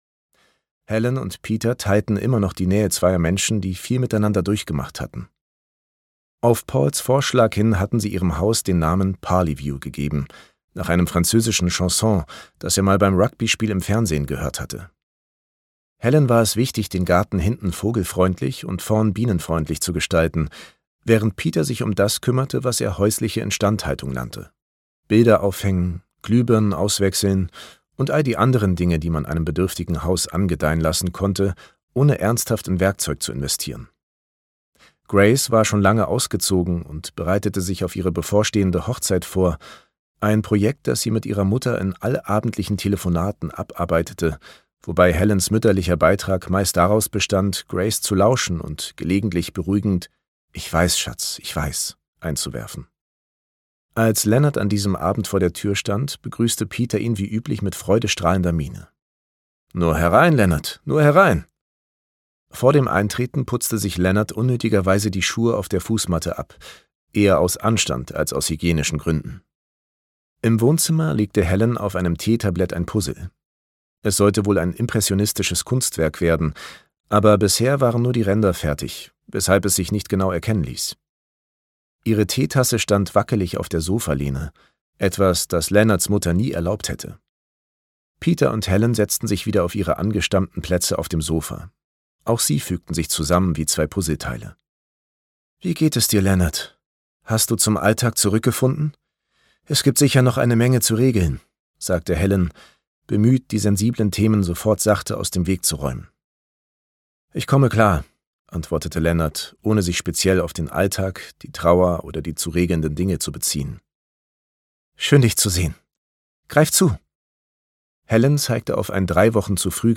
2024 Dumont Audiobook